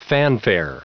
Prononciation du mot fanfare en anglais (fichier audio)